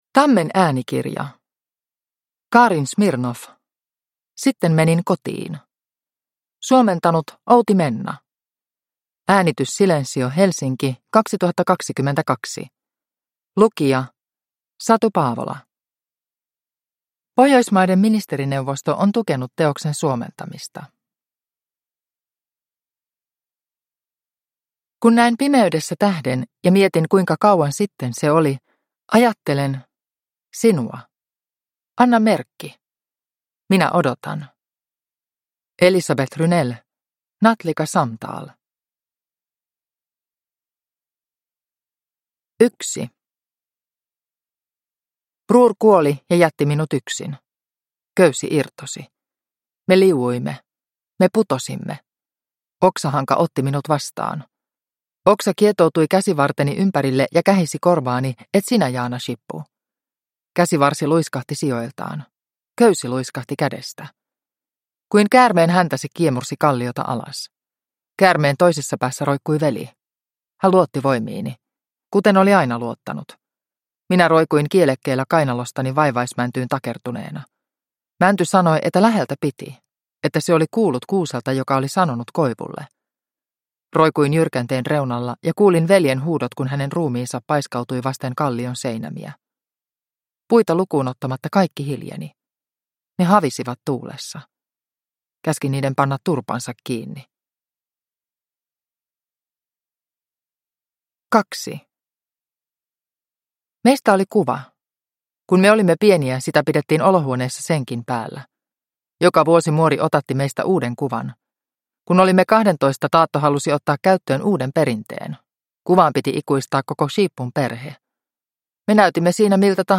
Sitten menin kotiin – Ljudbok – Laddas ner